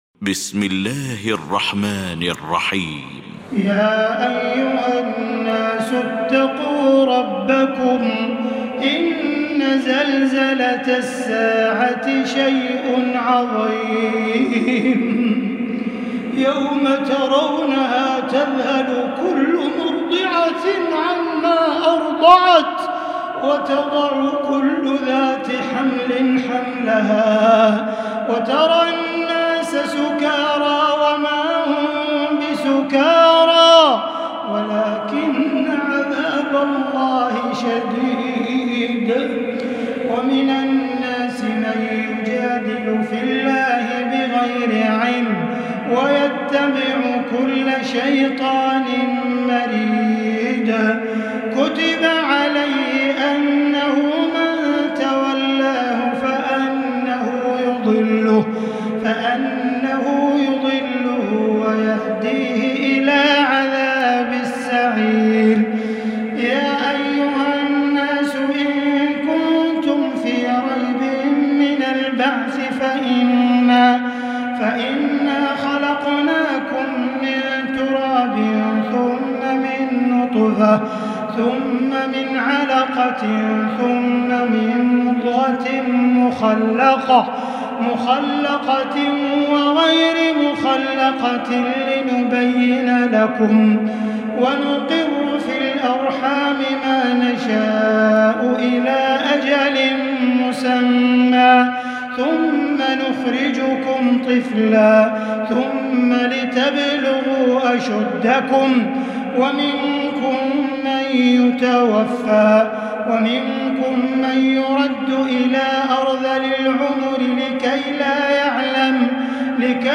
المكان: المسجد الحرام الشيخ: معالي الشيخ أ.د. بندر بليلة معالي الشيخ أ.د. بندر بليلة فضيلة الشيخ ياسر الدوسري الحج